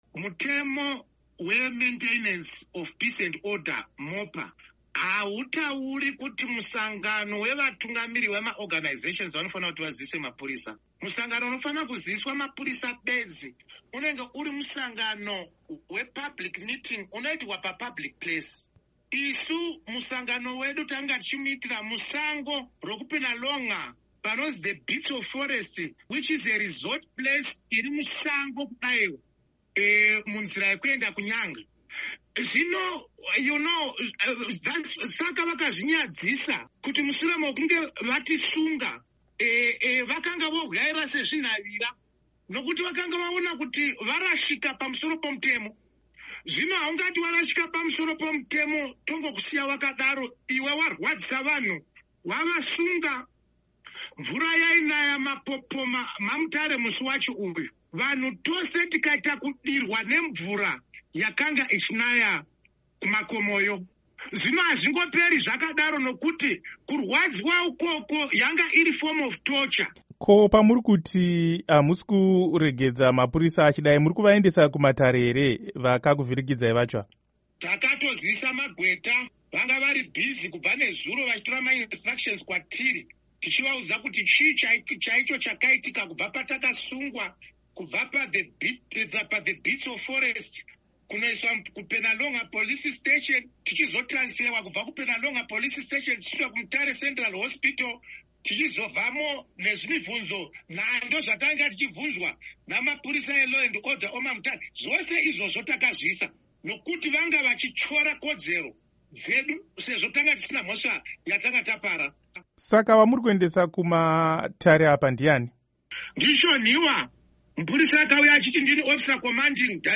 Hurukuro naVaJob Sikhala